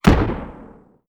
EXPLOSION_Arcade_18_mono.wav